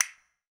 PCASTANET.wav